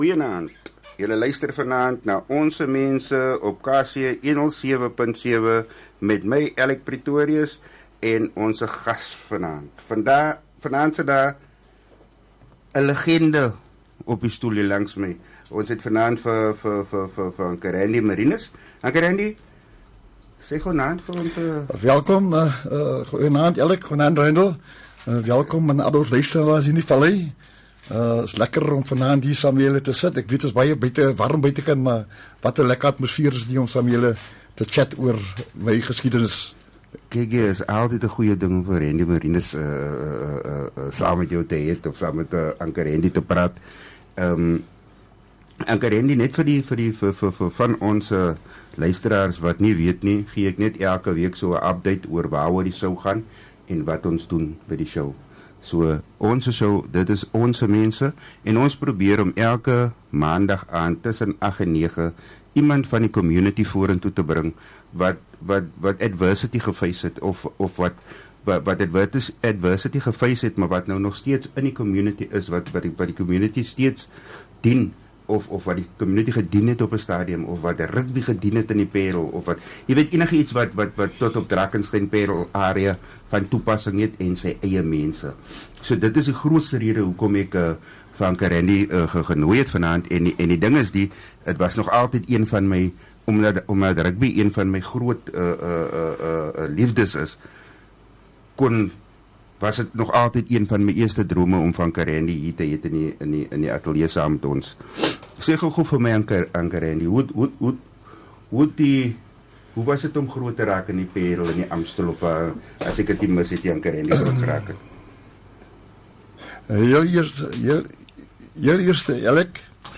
’n Eerlike en diep gesprek